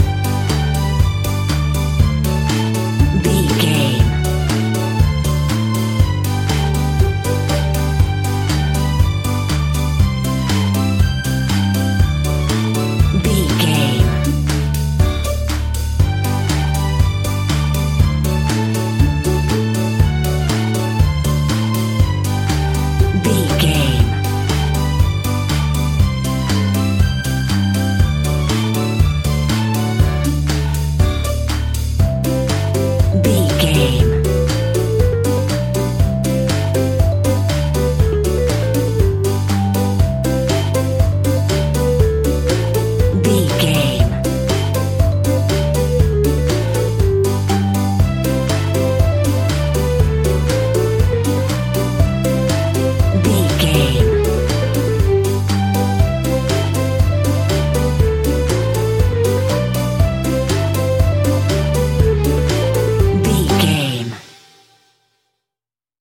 Uplifting
Ionian/Major
Fast
instrumentals
fun
childlike
cute
happy
kids piano